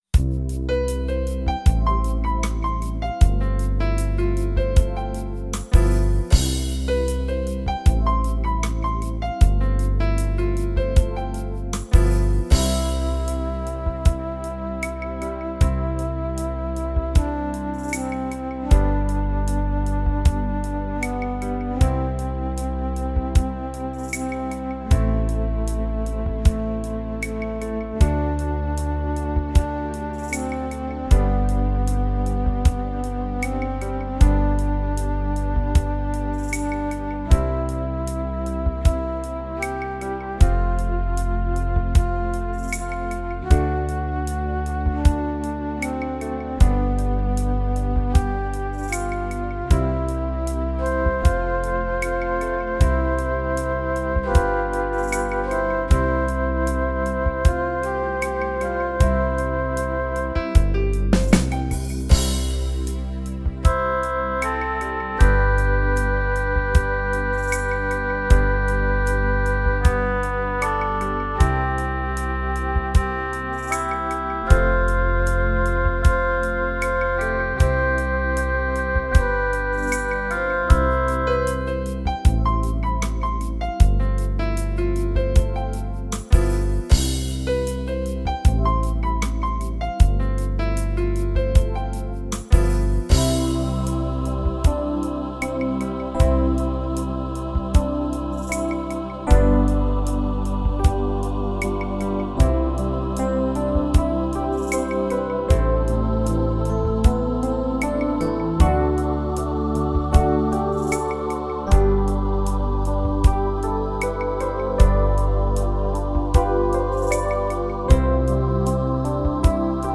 Playbacks-KARAOKE